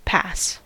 pass: Wikimedia Commons US English Pronunciations
En-us-pass.WAV